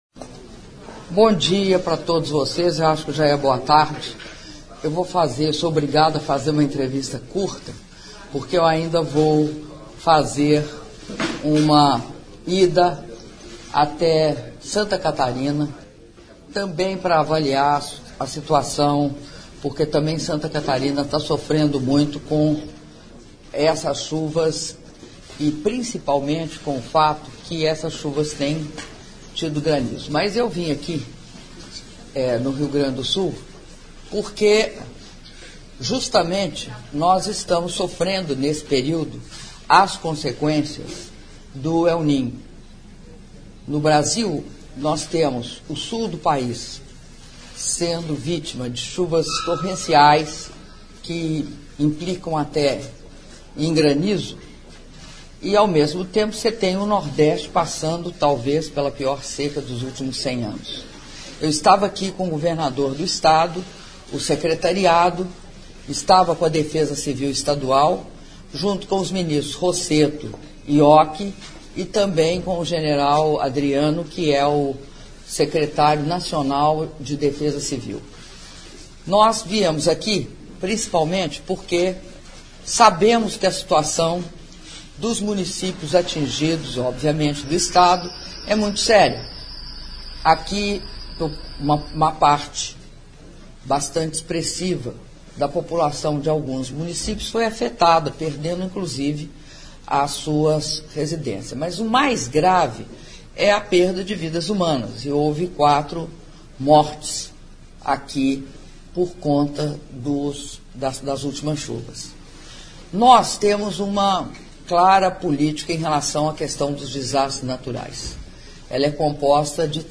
Áudio da entrevista concedida pela Presidenta da República, Dilma Rousseff, após visita á Canoas-RS(10min09s)